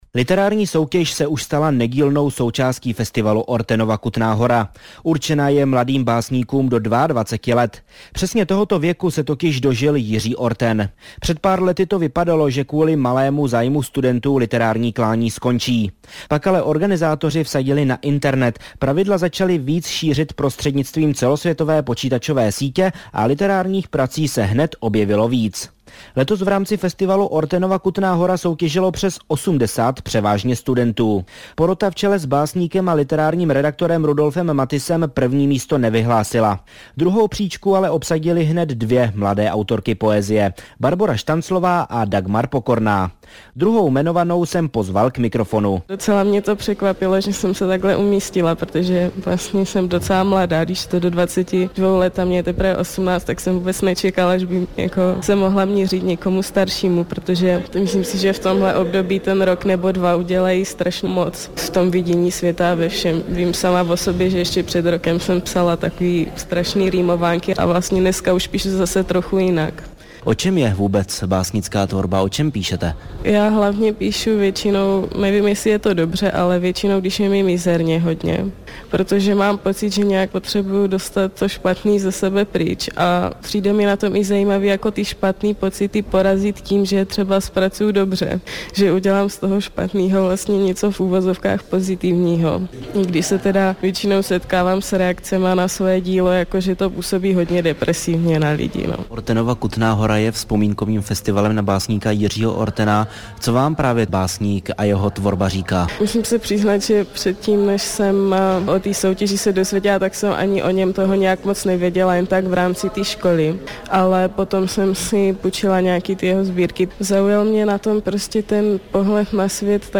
- zpráva rádia Vltava ze dne 7. 9. 2009, obsahuje i rozhovor se mnou  - stáhnout